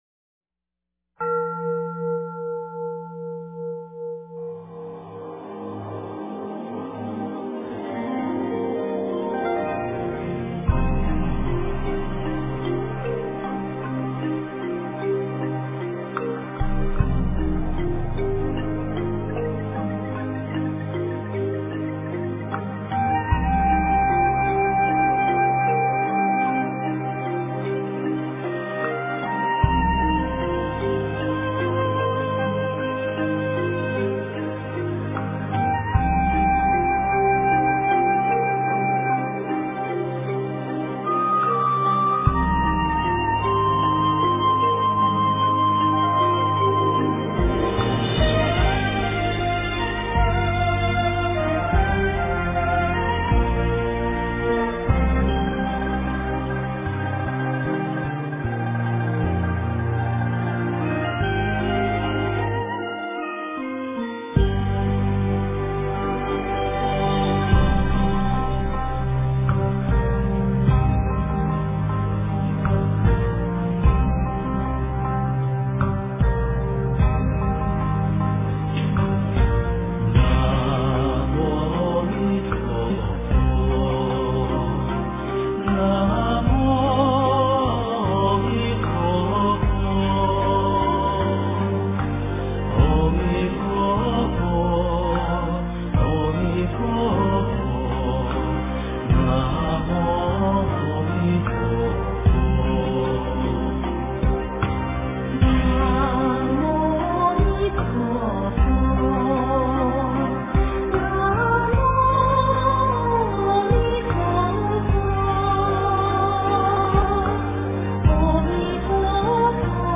南无阿弥陀佛--男女6音调
南无阿弥陀佛--男女6音调 经忏 南无阿弥陀佛--男女6音调 点我： 标签: 佛音 经忏 佛教音乐 返回列表 上一篇： 晚课--女众 下一篇： 大悲咒+楞严咒十小咒--僧团 相关文章 三稽首--如是我闻 三稽首--如是我闻...